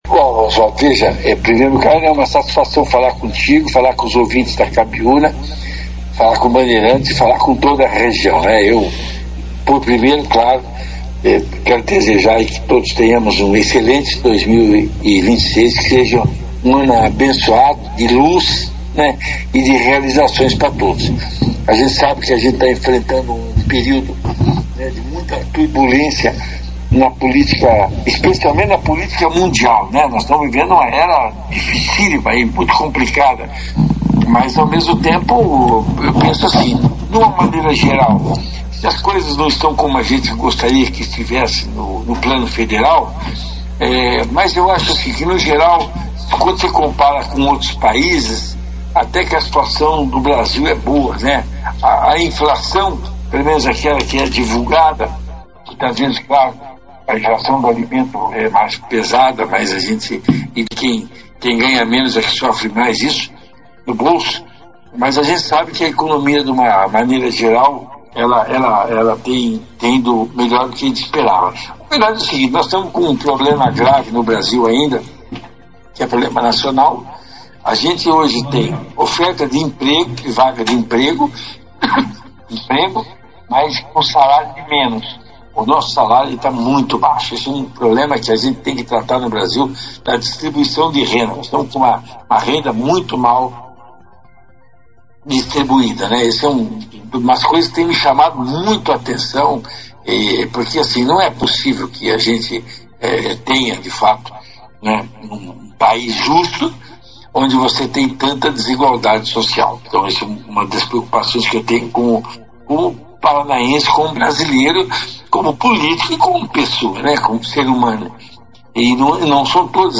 Deputado Luiz Claudio Romanelli destaca investimentos e das perspectivas para 2026 em entrevista ao Operação Cidade - Rádio Cabiuna
O deputado estadual Luiz Claudio Romanelli participou, nesta terça-feira, 13 de janeiro, da 2ª edição do jornal Operação Cidade.